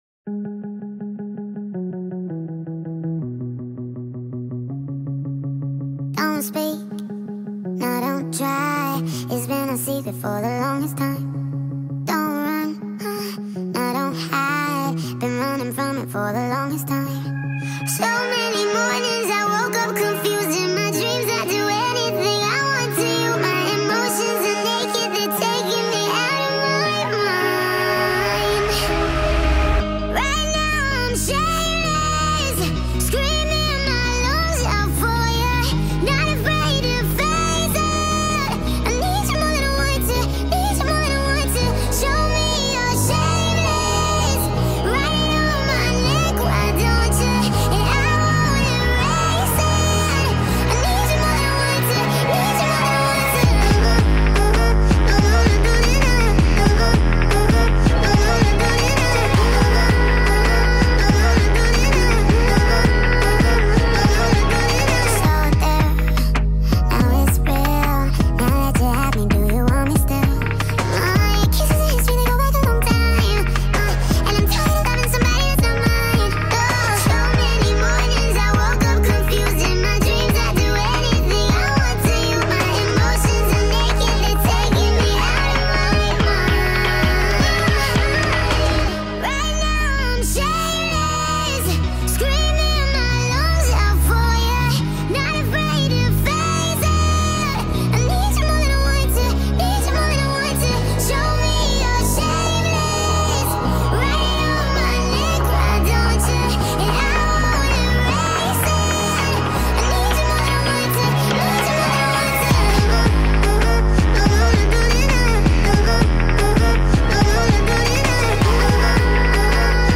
sped up remix